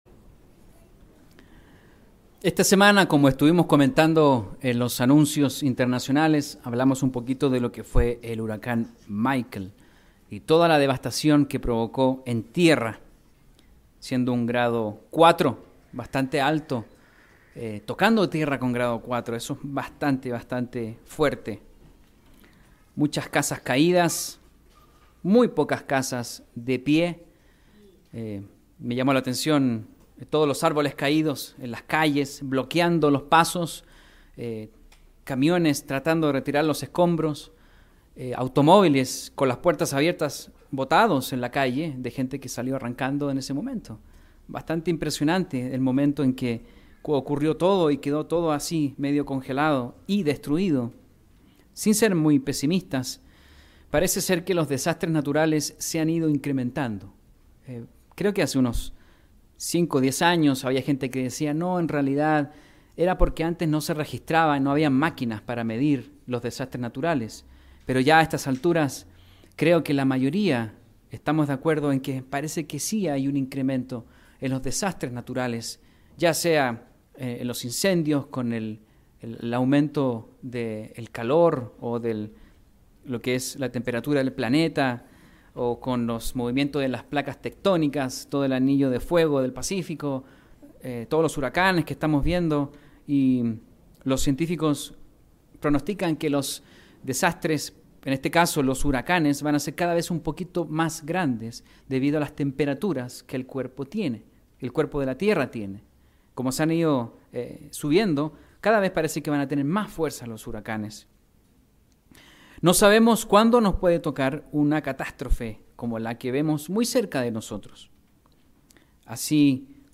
Esto puede ser tomado como una analogía respecto a los cinco aspectos más importantes para mantener una relación cercana con Dios, que nos permitirán enfrentar al adversario. Mensaje entregado el 13 de octubre de 2018.